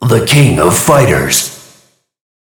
KOFTitleCall.ogg